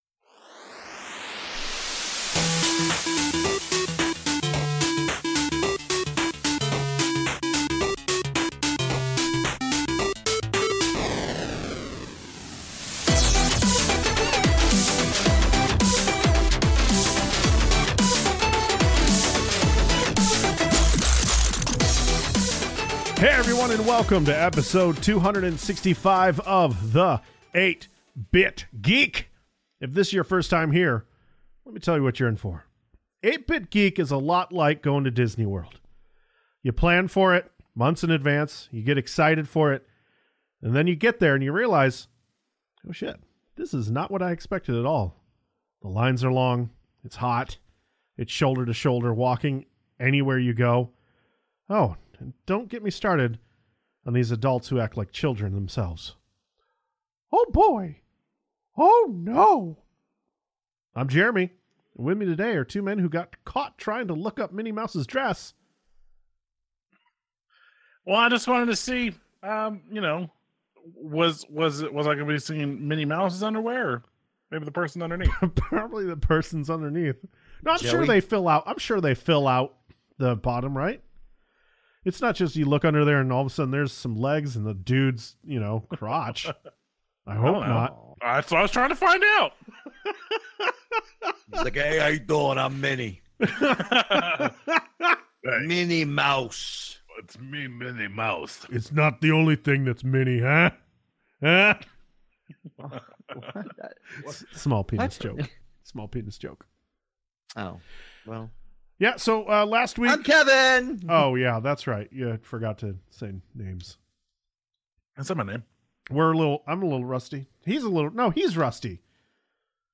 Sorry for any audio issues on this weeks episode. We had some technical issues with mics.